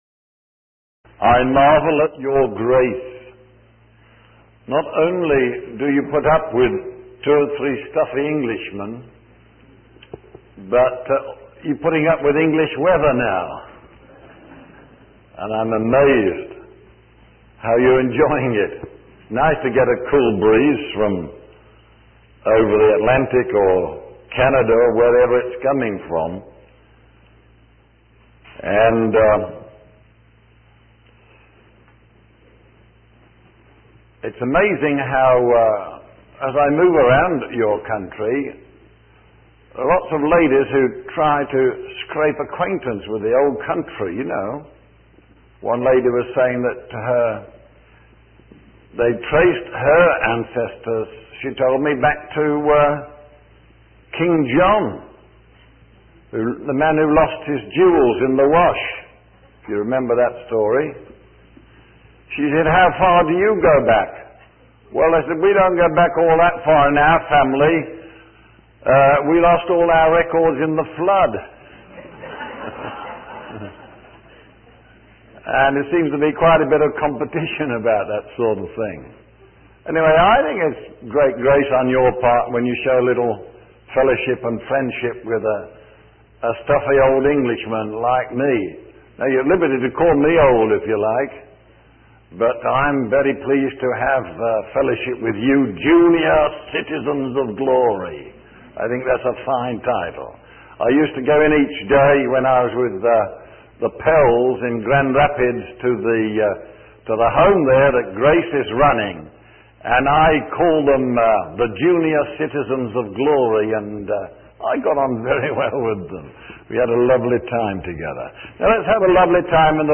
In this sermon, the speaker focuses on the prophecy by Daniel in chapter two, verse 31.